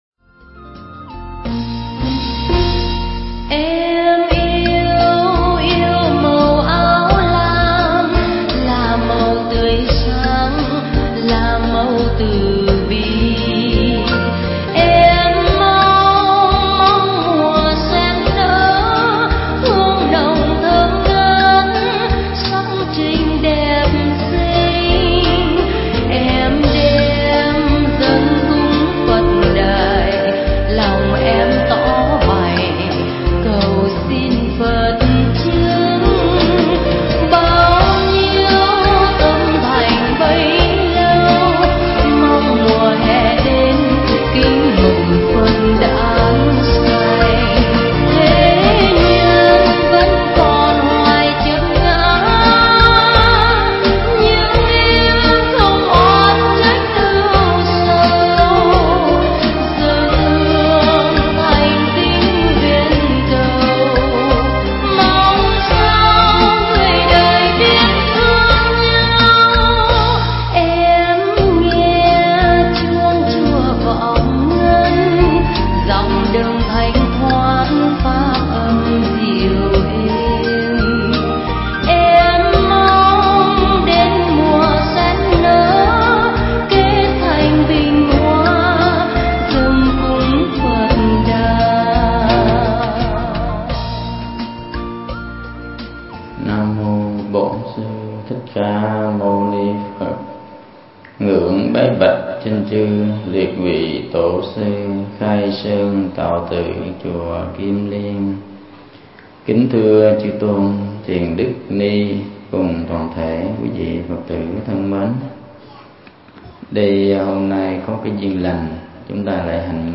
Mp3 Thuyết Pháp Hạ cánh trên Sen Vàng